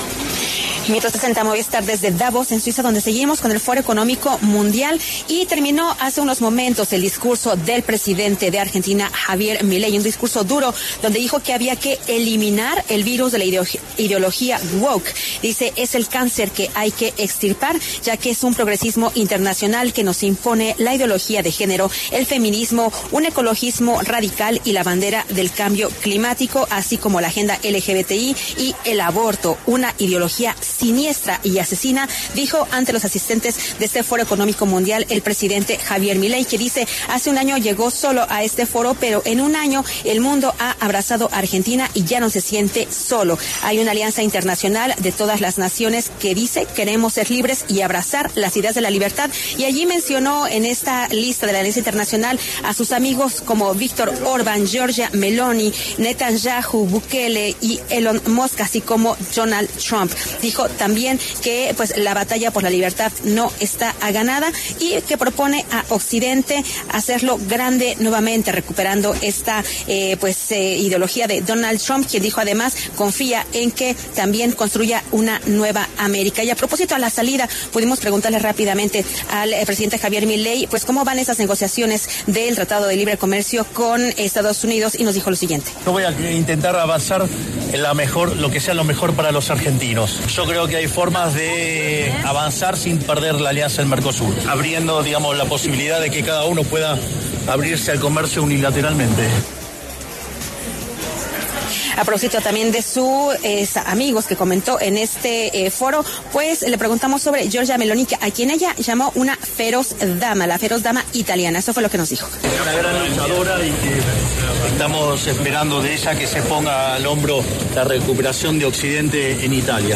Desde allí, habló para los micrófonos de La W sobre cómo avanzan las negociones del Tratado de Libre Comercio con Estados Unidos.